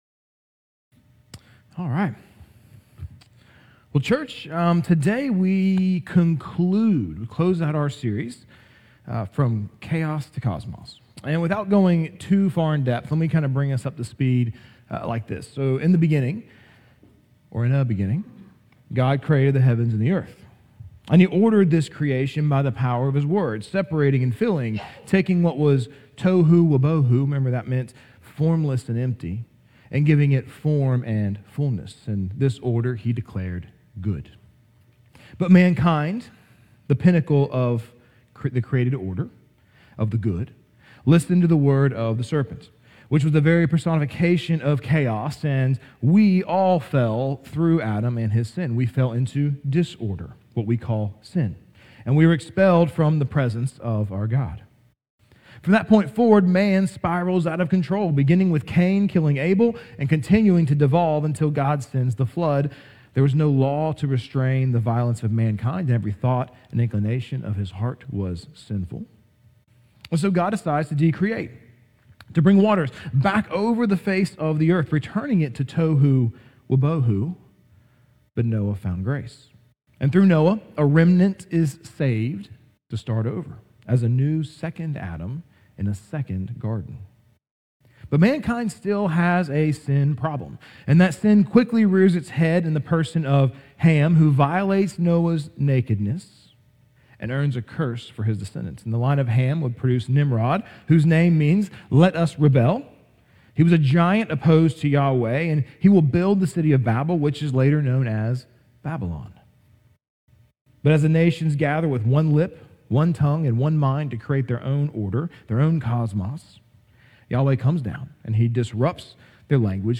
Sermons | Grace Fellowship (EPC)